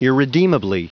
Prononciation du mot irredeemably en anglais (fichier audio)
Prononciation du mot : irredeemably